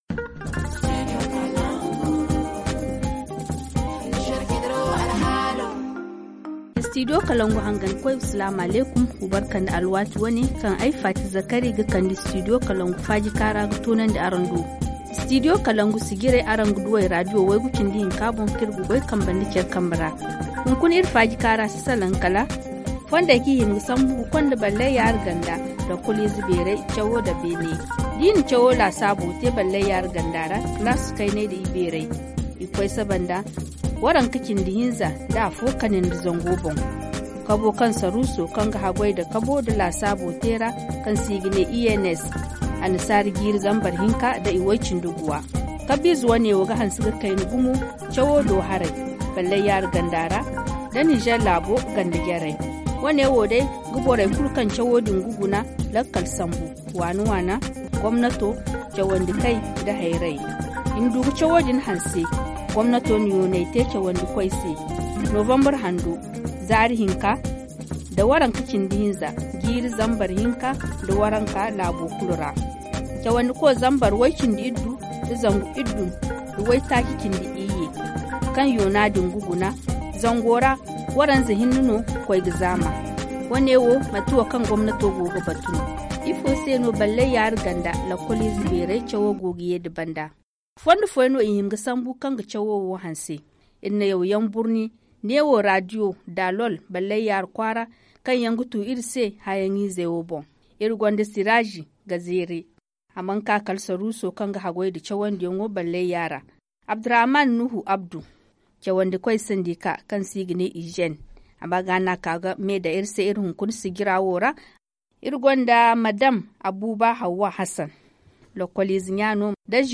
[Rediffusion] Comment rehausser le niveau des élèves du secondaire dans le département de Balleyara - Studio Kalangou - Au rythme du Niger